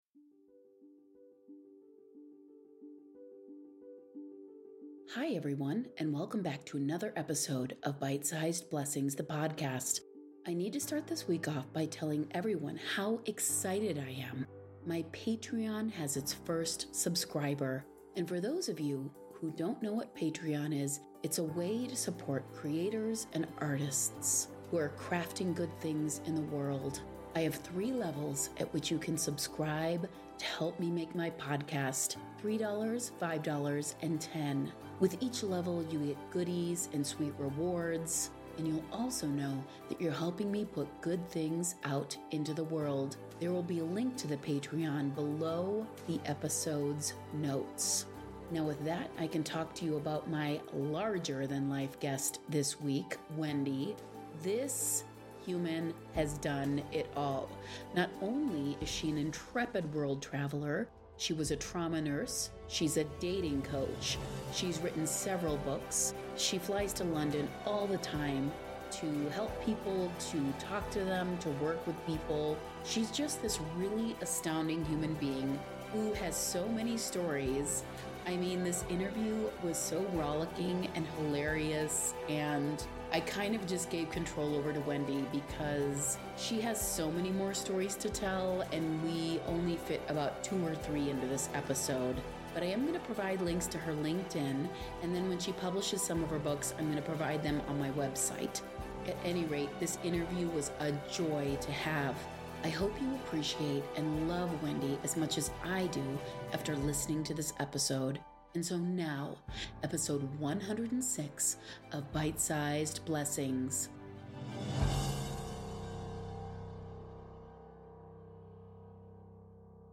Listen in while she tells just one of her stories in this shorter interview!